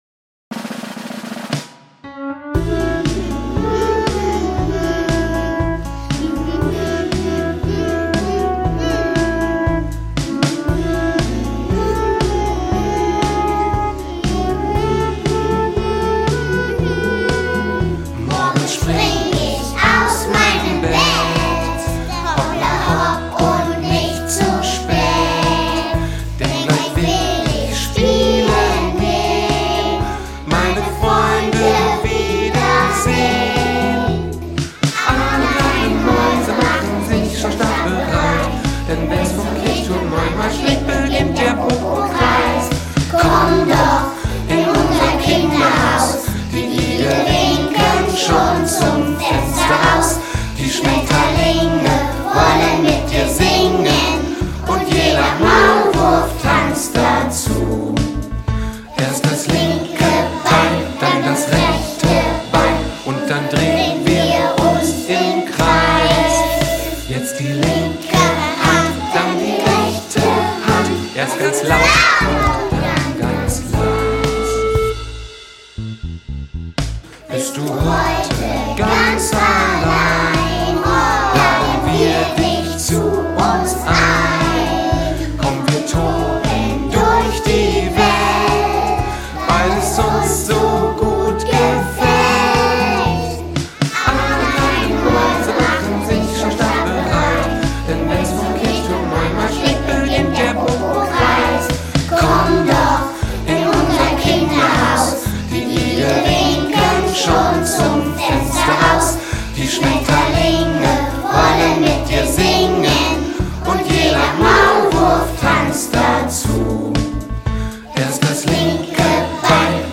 Im kleinen Aufnahmestudio der Musikschule haben Kinder aus allen Kinderhaus-Gruppen den Song dann eingesungen.
2-Kids-Version.mp3